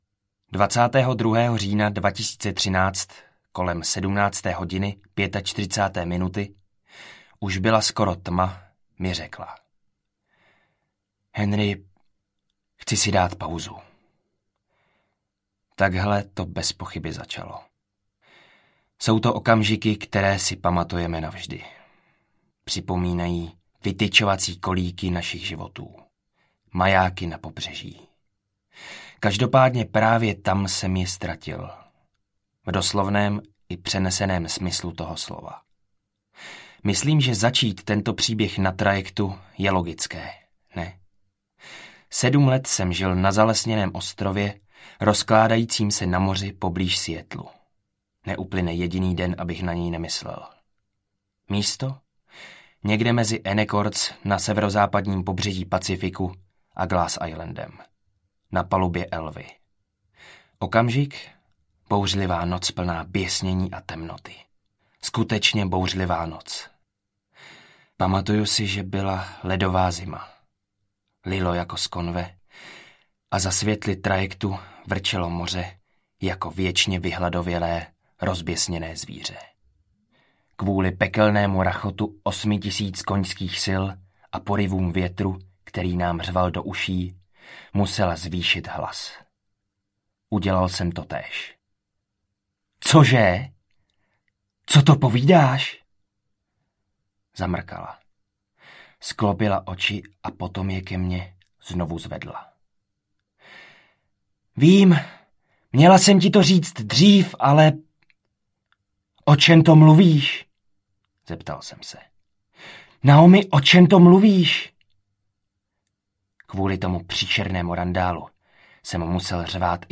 Zkurvenej příběh audiokniha
Ukázka z knihy